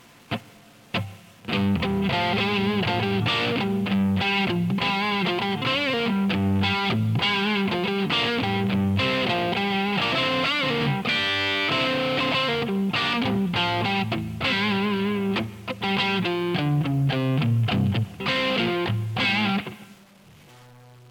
Quick Improvised Sound Clip